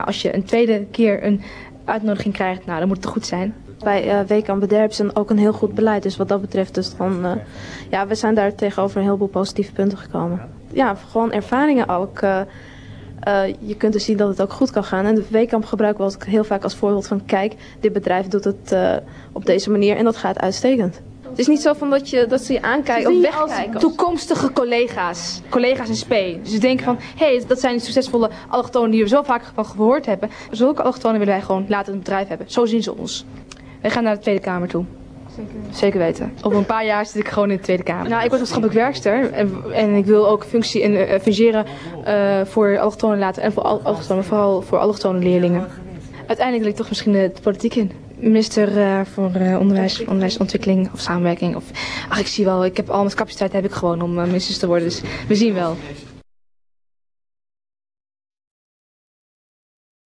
Poldernederlands
Op 27 april jl. hoorde ik in het programma Formule 1 op Radio 1 enkele meisjes praten in Poldernederlands van het zuiverste water. Het ging over hun ambities in het bedrijfsleven, die gigantisch waren, en hun naar eigen zeggen bijzondere kwaliteiten om straks belangrijke functies in de politiek te bekleden.
allochtone_meisjes.ra